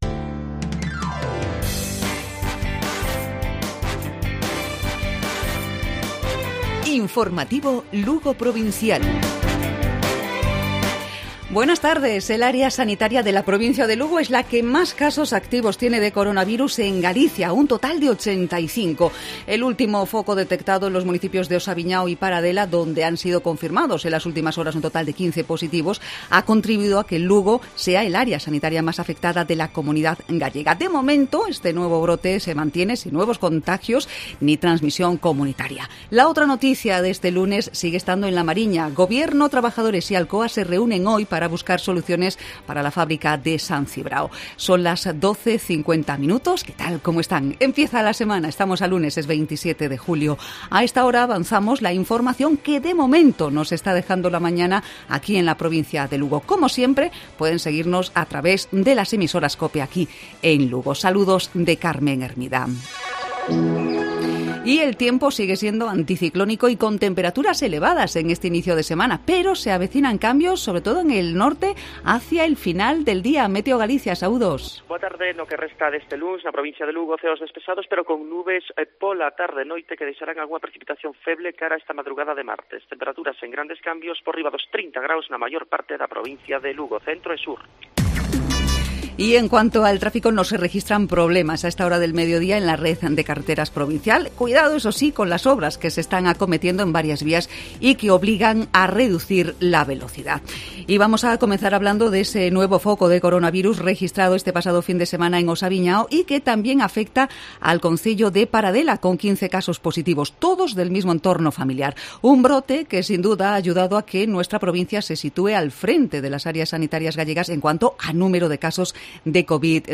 Informativo Provincial Cope Lugo.